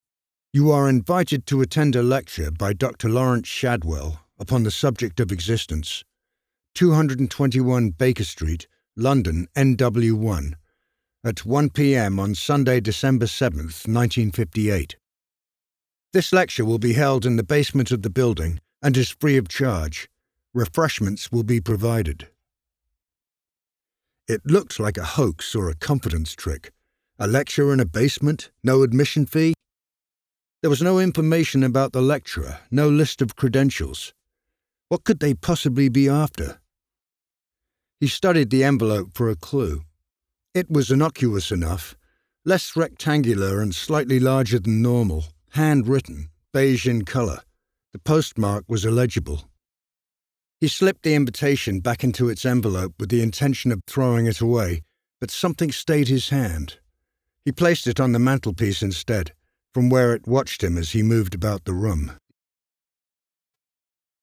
Fabian, a Cubist Biography is available as an audio book